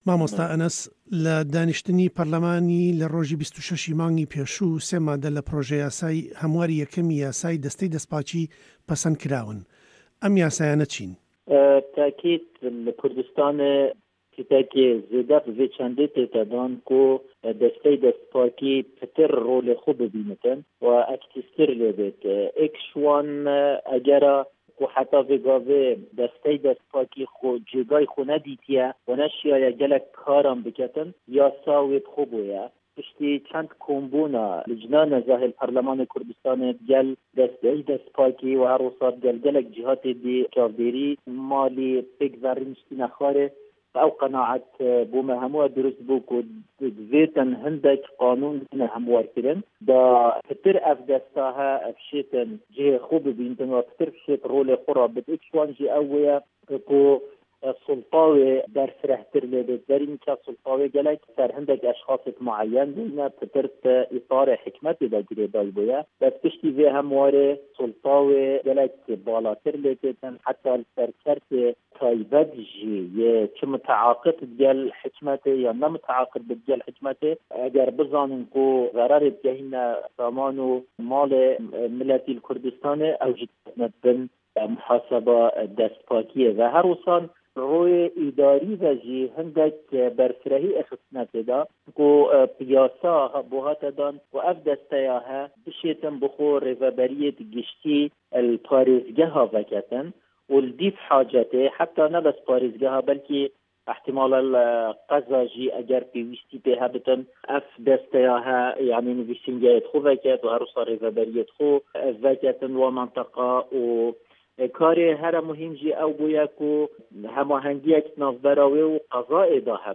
هه‌رێمه‌ کوردیـیه‌کان - گفتوگۆکان
ئه‌مه‌ ته‌وه‌ری گفتووگۆی ئه‌نه‌س محه‌مه‌د شه‌ریف دۆسکیه‌ ئه‌ندامی په‌رله‌مانی هه‌رێمی کوردستانه‌ بۆ ده‌نگی ئه‌مریکا.